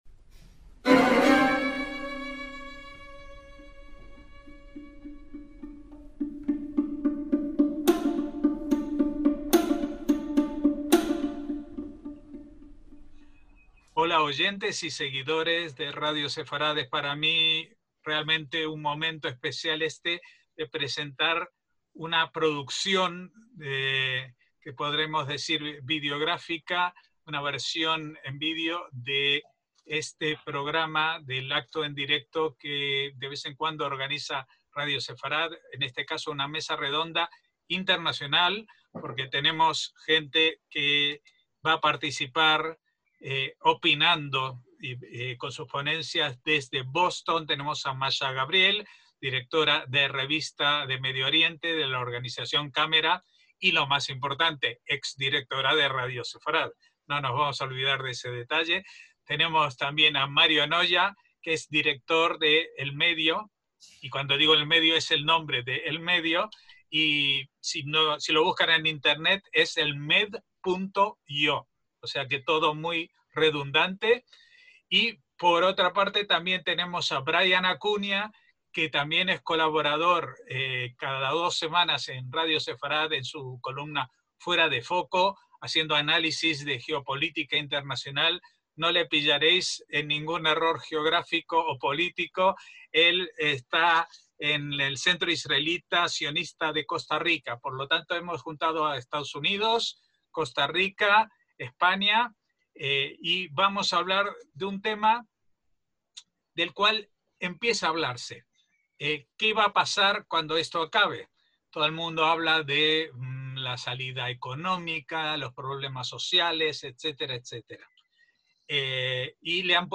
ACTOS "EN DIRECTO" - El 27 de mayo de 2020 tuvo lugar una mesa redonda internacional organizada por Radio Sefarad sobre la imagen de Israel en la era de la Nueva Normalidad que se supone sucederá al desconfinamiento por la pandemia del COVID-19.